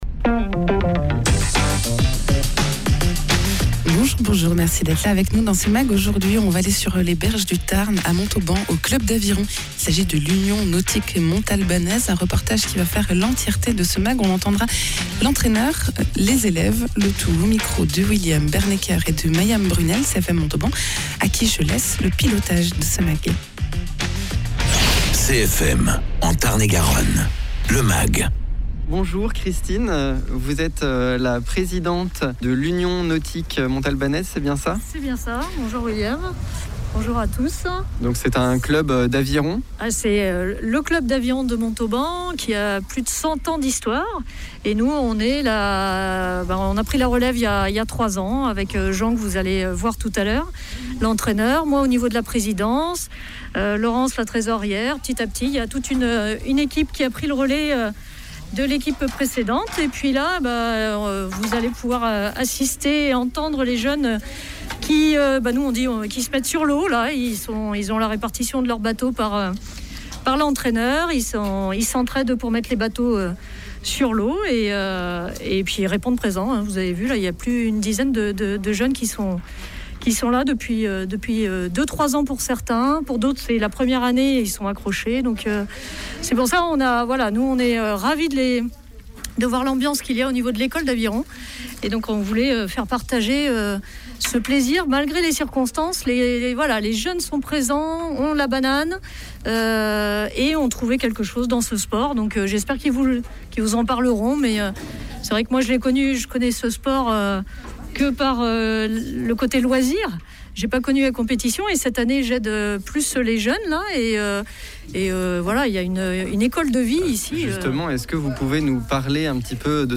Un tour sur les berges du Tarn à Montauban, au club d’aviron, l’Union Nautique Montalbanaise.